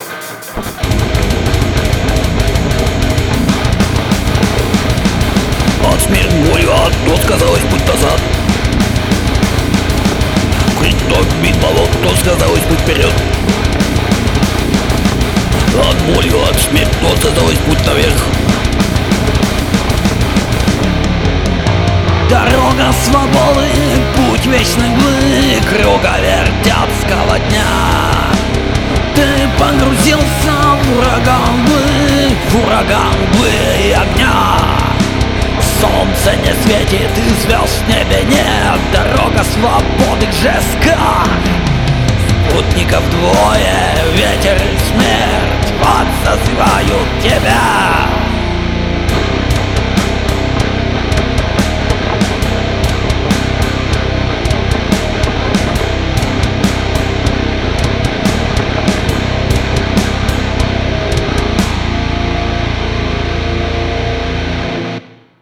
Death metal ���������